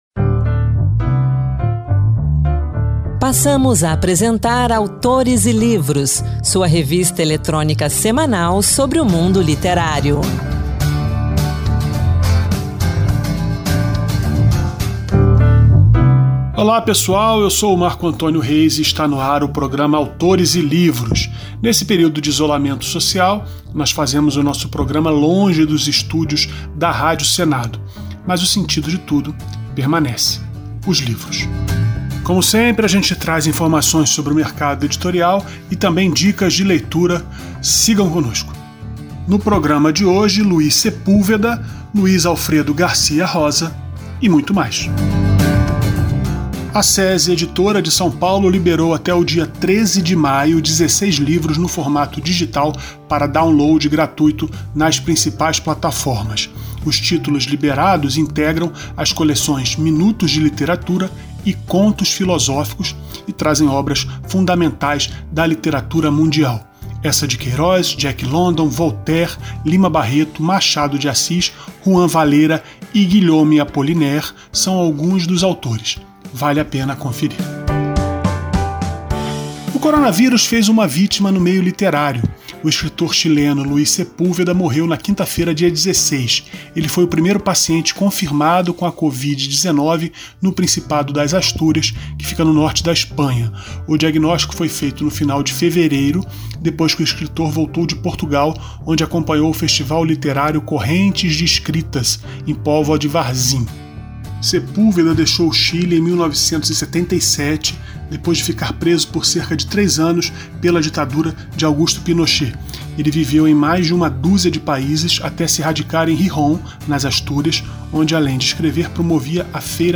O Autores e Livros traz uma entrevista com o escritor de romances policiais Luiz Alfredo Garcia-Roza, falecido na semana passada. O programa também lembra outro escritor que morreu há pouco tempo, o chileno Luís Sepúlveda.